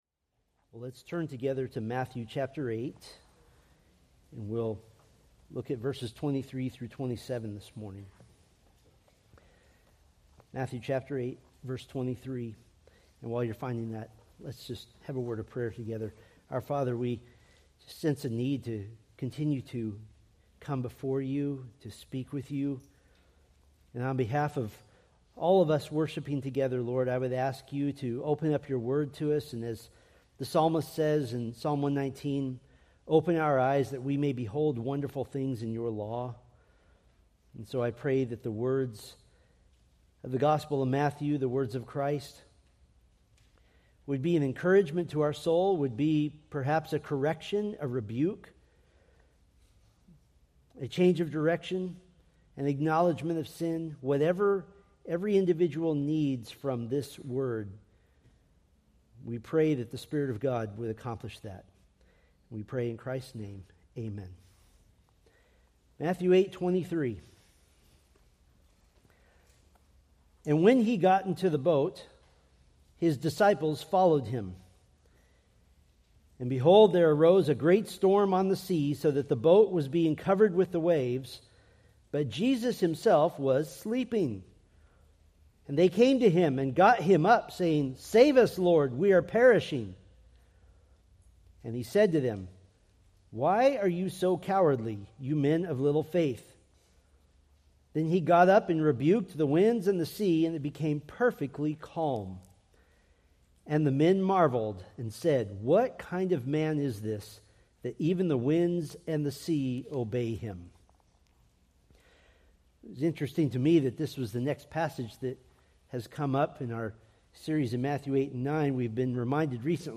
Sermon Details